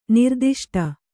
♪ nirdiṣṭa